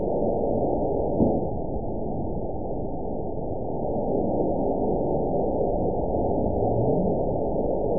event 917215 date 03/24/23 time 11:34:33 GMT (2 years, 1 month ago) score 9.32 location TSS-AB04 detected by nrw target species NRW annotations +NRW Spectrogram: Frequency (kHz) vs. Time (s) audio not available .wav